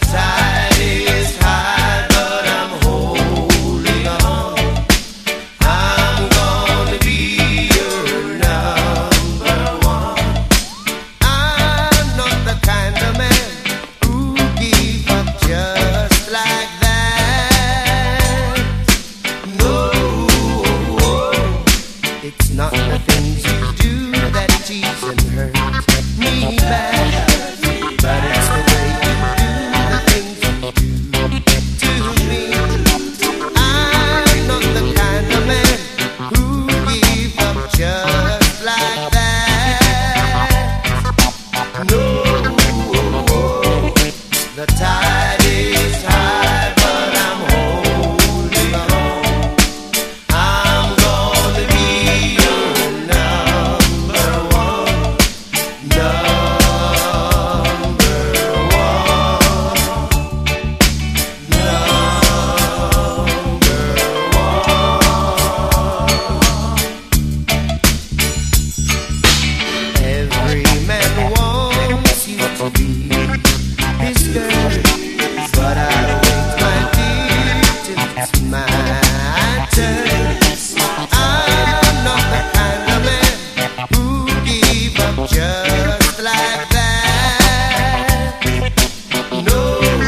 WORLD / RHYTHM & BLUES / JUMP BLUES